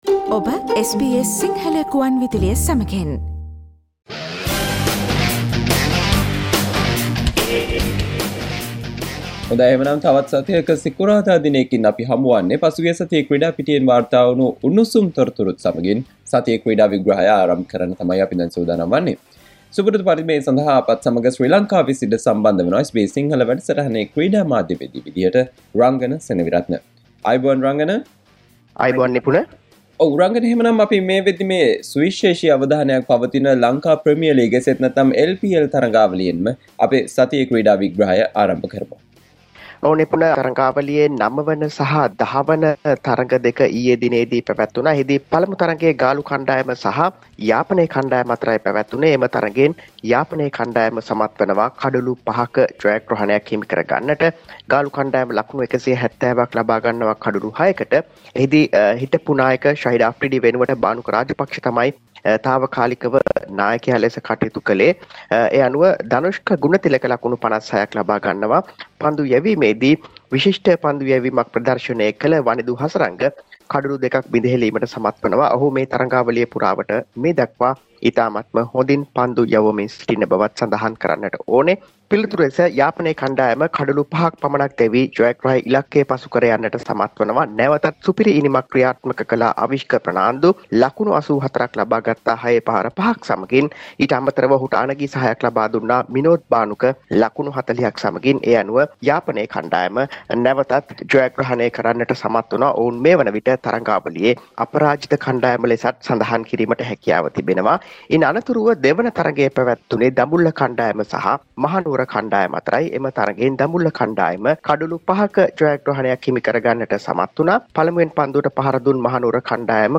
SBS Sinhala radio weekly Sports Wrap